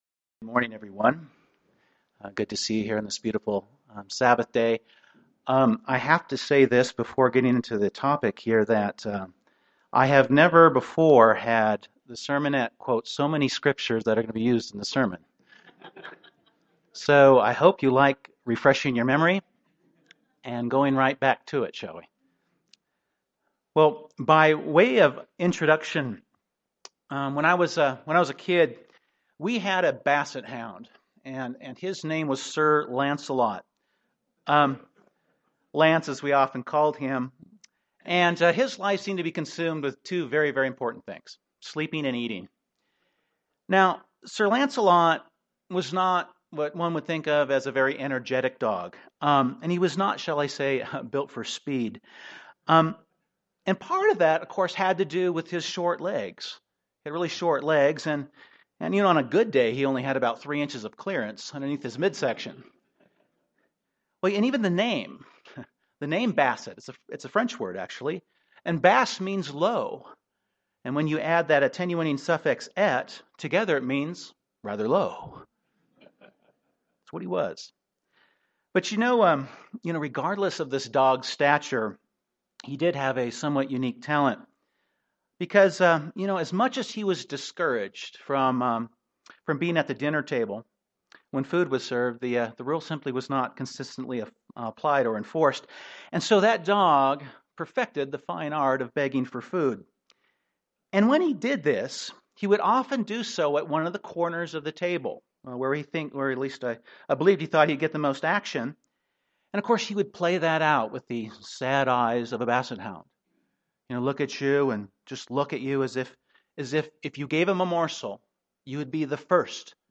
This sermon explores the subject of overcoming temptation with a primary focus on how successfully defeating temptation requires a single mindset – the mind of Jesus Christ being in you (Philippians 2:5) as opposed to the shortcomings that come from a double mind (James 1:8).
UCG Sermon Studying the bible?